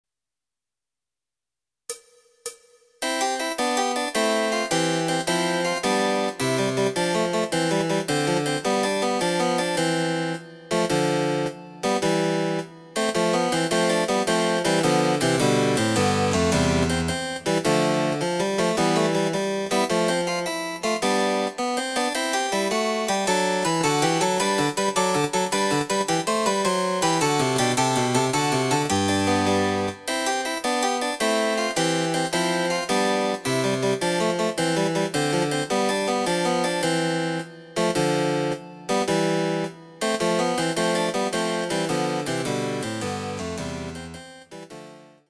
その他の伴奏
第４楽章　非常に遅い